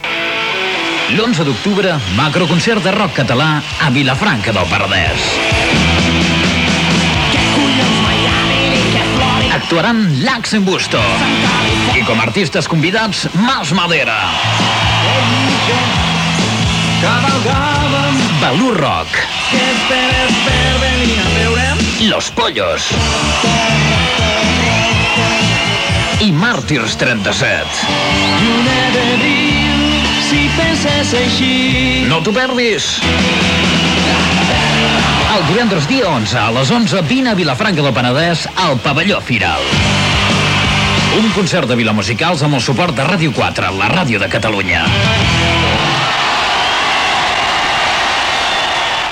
Publicitat del concert de rock català a Vilafranca del Panadès Gènere radiofònic Publicitat